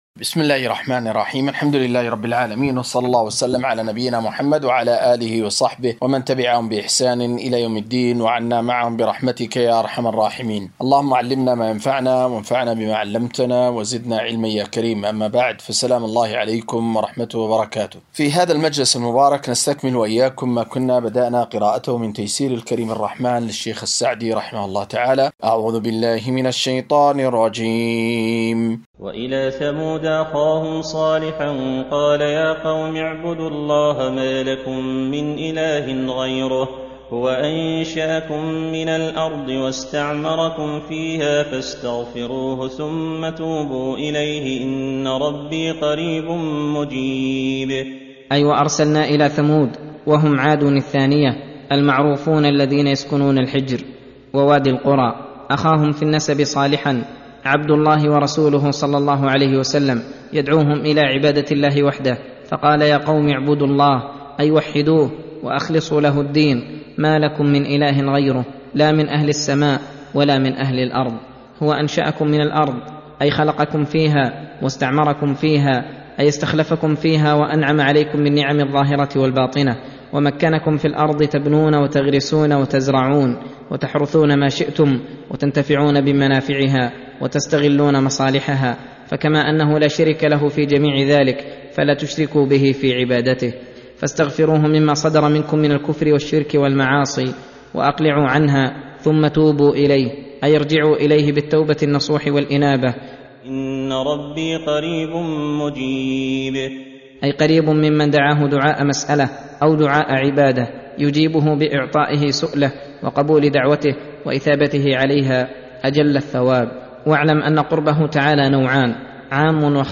قراءة وتعليق